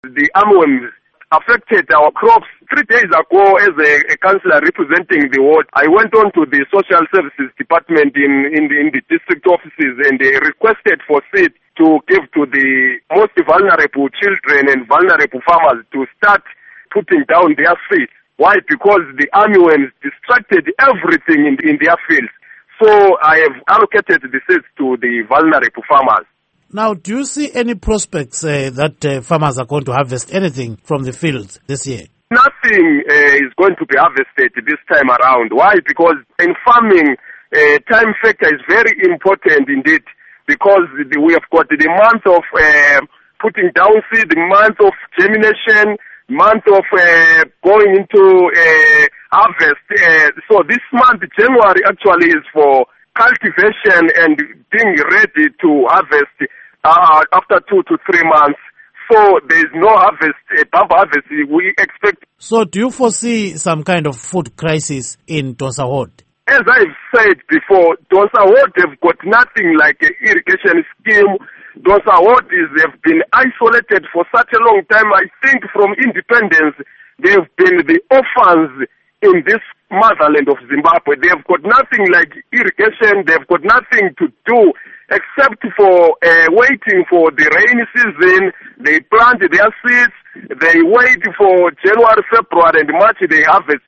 Interview With Willard Moyo